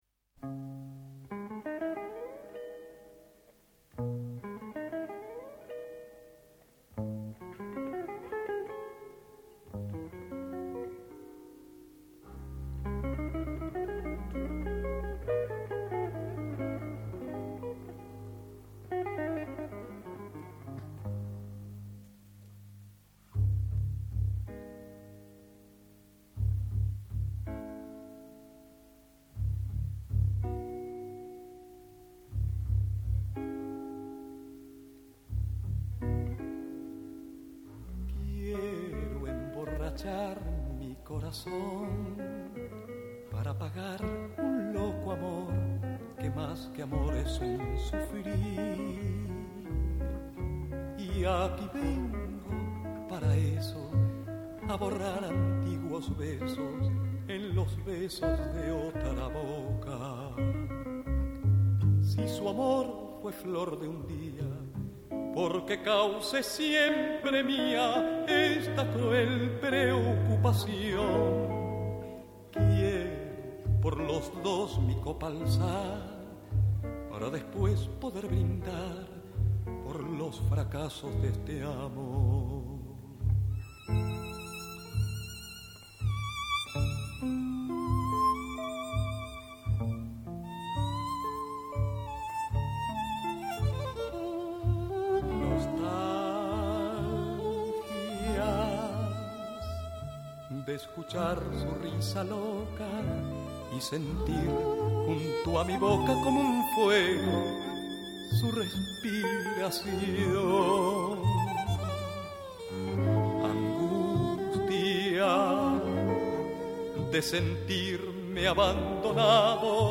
singer